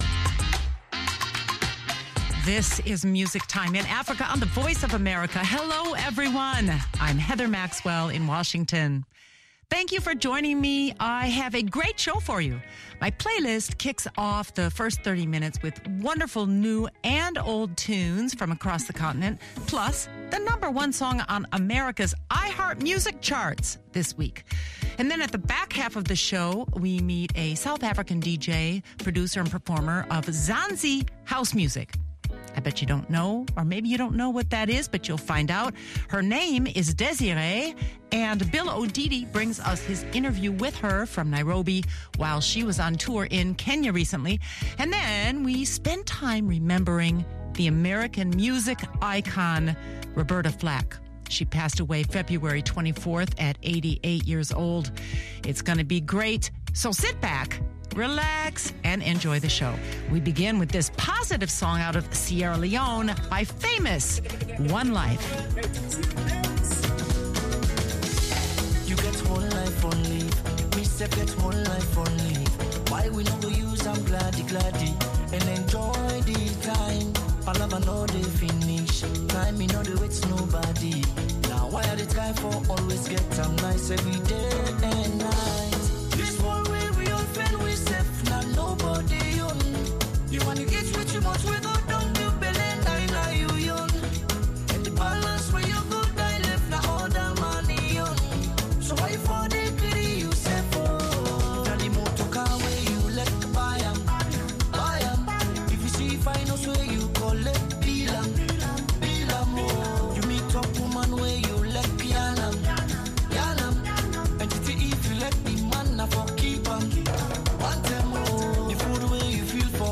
Music Time in Africa is VOA’s longest running English language program. Since 1965 this award-winning program has featured pan African music that spans all genres and generations.